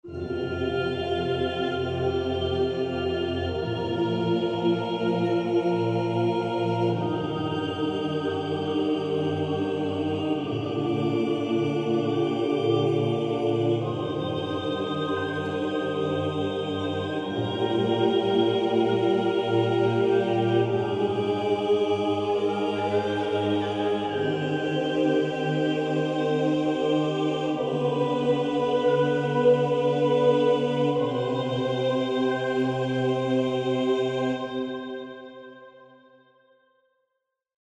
classique - orchestre - opera - vieux - chef